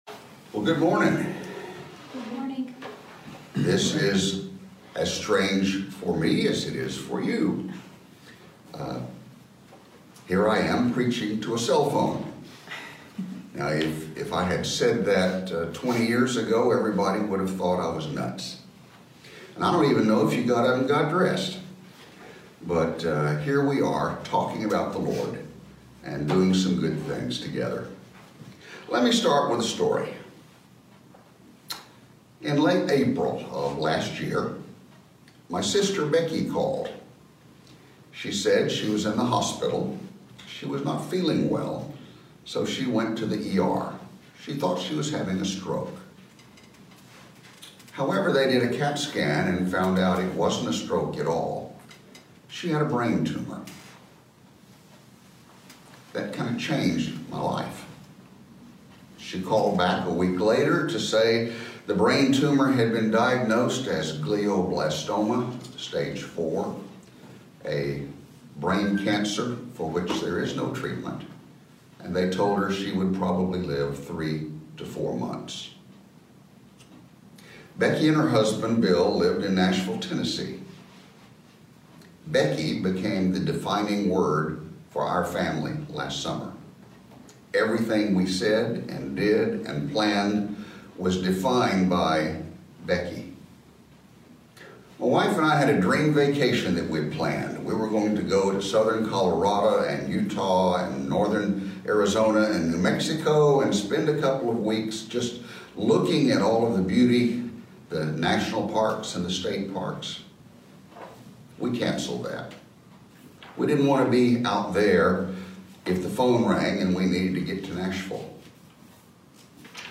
Weekly Sermon Audio “Trust God”